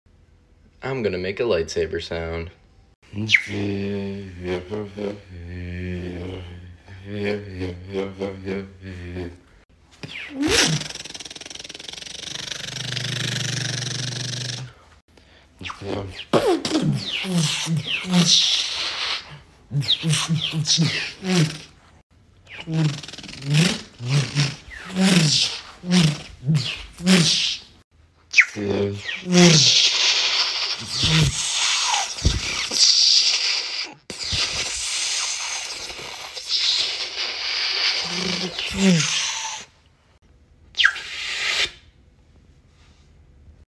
Making Lightsaber Sounds With My Sound Effects Free Download